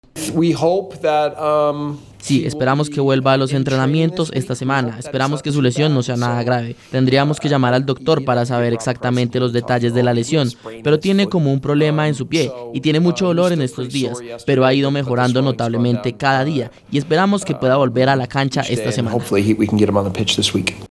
Jesse Marsch - DT Leeds, en rueda de prensa
VOZ-MARSCH.mp3